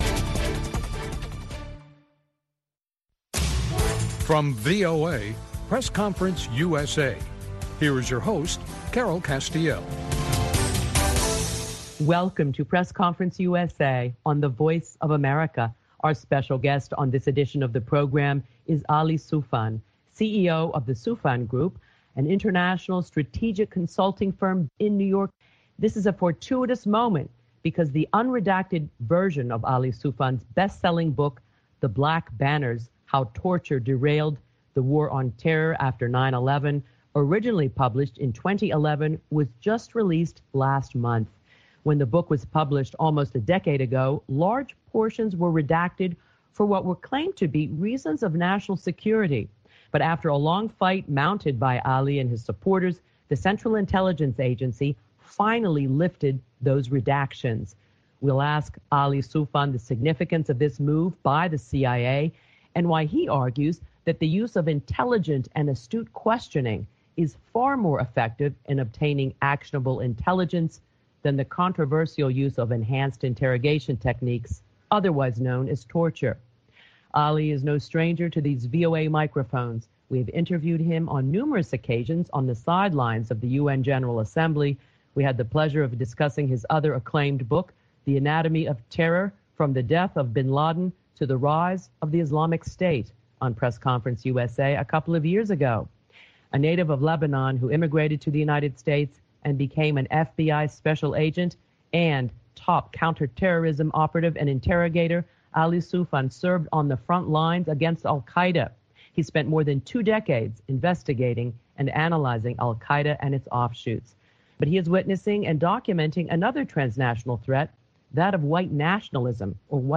A Conversation with Ali Soufan